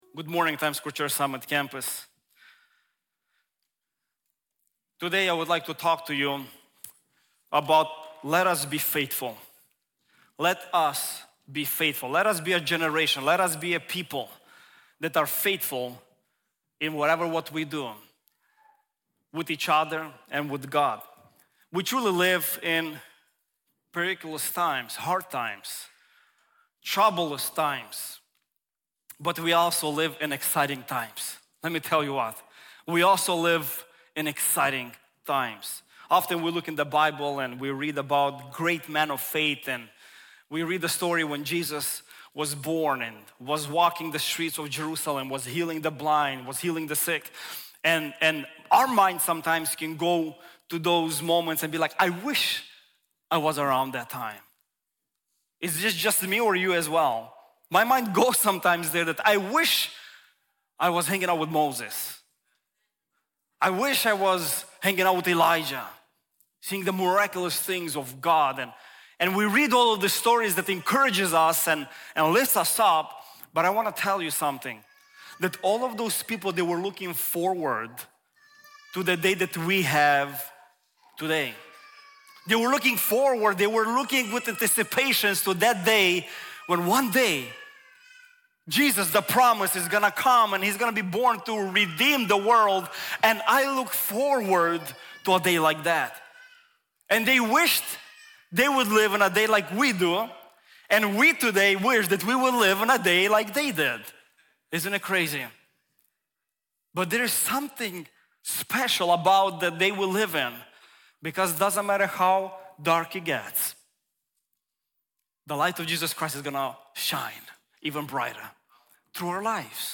Our messages are recorded at Times Square Church in New York City.